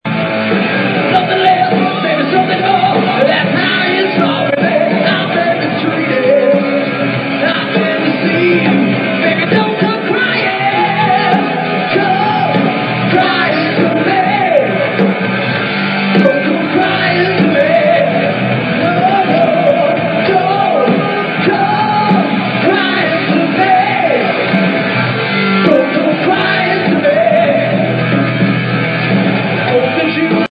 previously unreleased songs played live
drums, lead vocals, percussion
bass
guitar